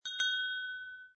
SFX在门上的风铃欢迎风吹车铃音效下载
SFX音效